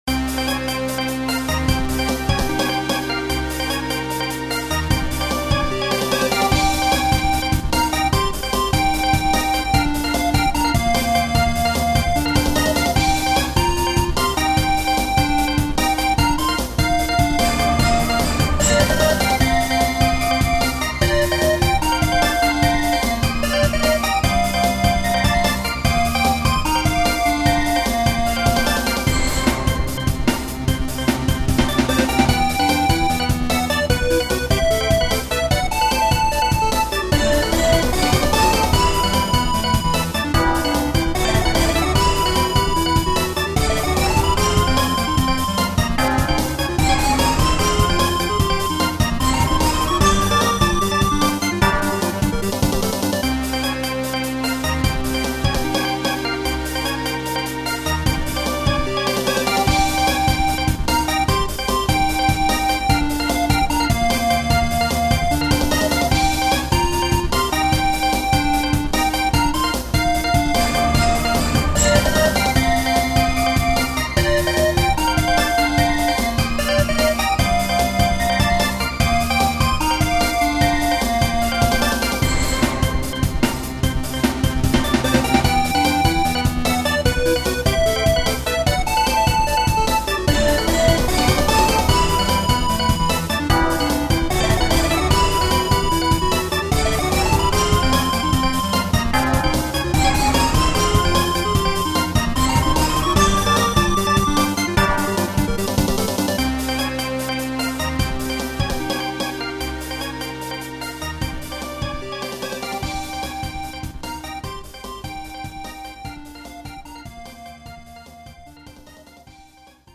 こ、こんなに難易度が高いとは思いませんでした(汗)『おいつめられて』なんざ物の数にならないくらい音が詰まってます。